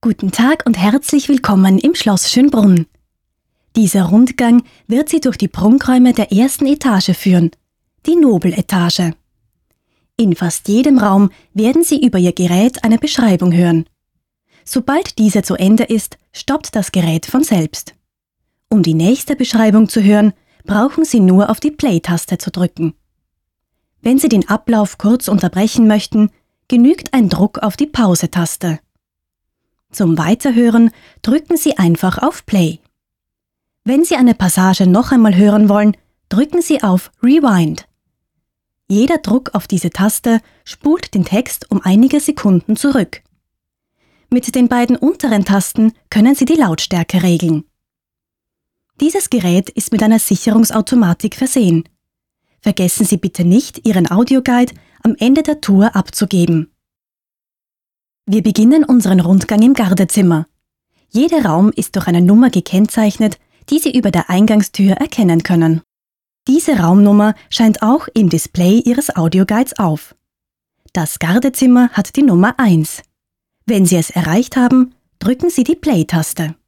wienerisch
Sprechprobe: eLearning (Muttersprache):